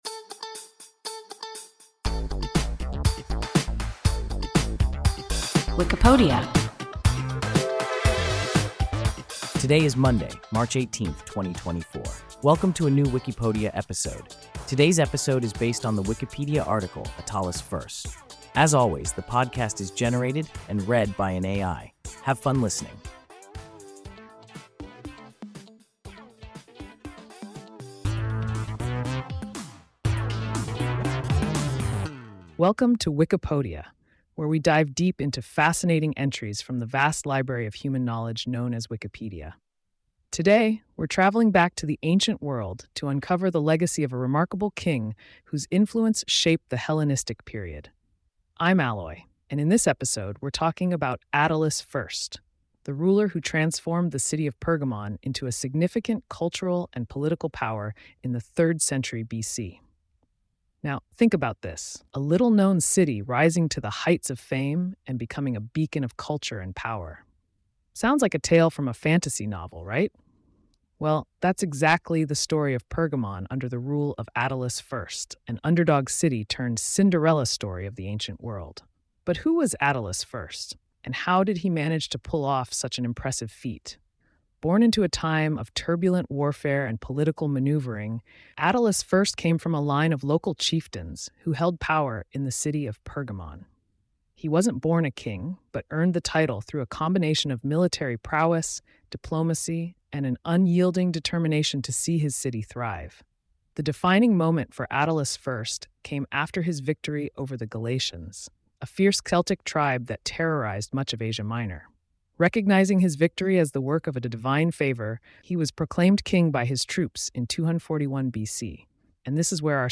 Attalus I – WIKIPODIA – ein KI Podcast